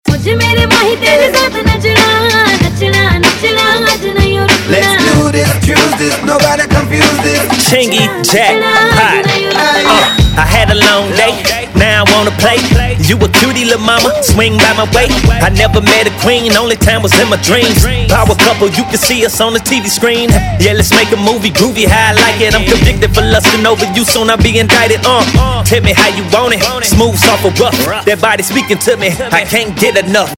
Pop Ringtones